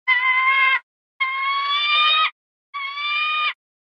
Звуки соколов
На этой странице собраны разнообразные звуки, издаваемые соколами: от громких охотничьих криков до нежного щебетания птенцов.